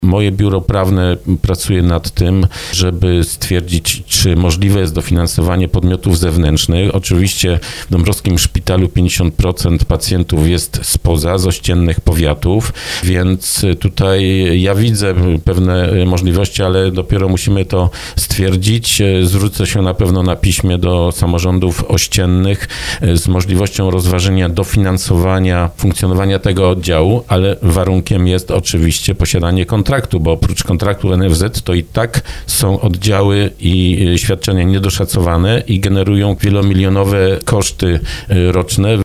– Trwają starania, aby nie było przerwy w pracy oddziału ginekologiczno-położniczego w szpitalu w Dąbrowie Tarnowskiej – zapewniał na antenie RDN Małopolska starosta dąbrowski Lesław Wieczorek.